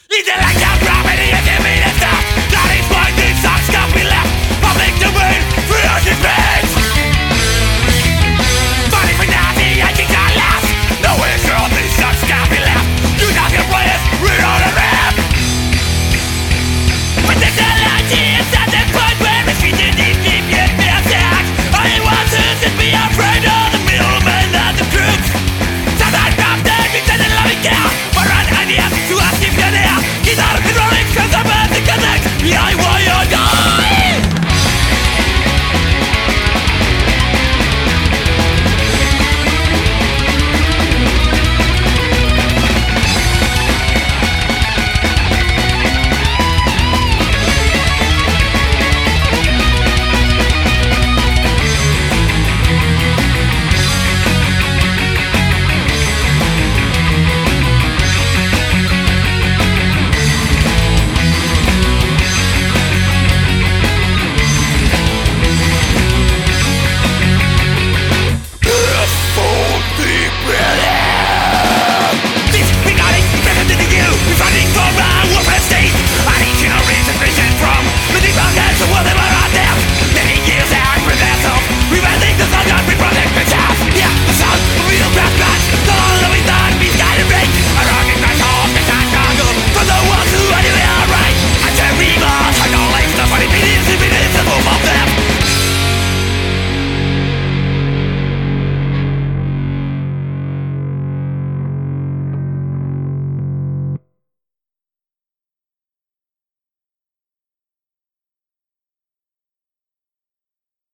Style: Hard Punk Rock